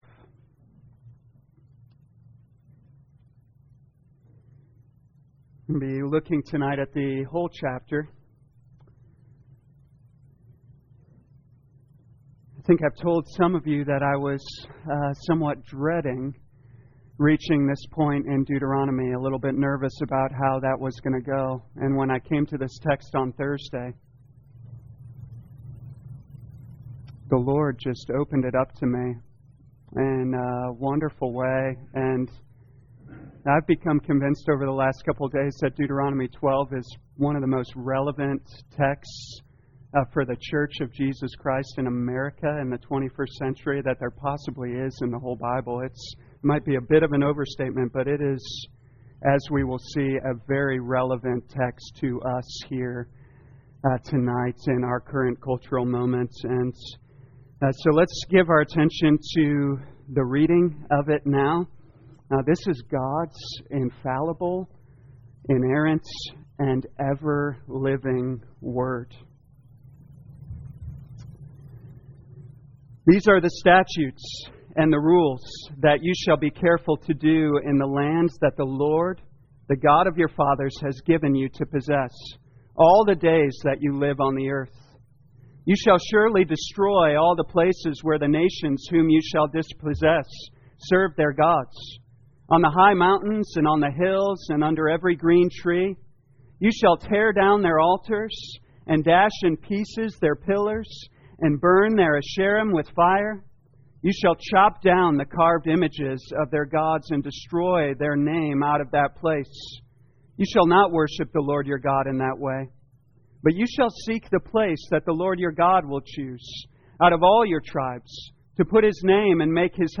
2022 Deuteronomy The Law Evening Service Download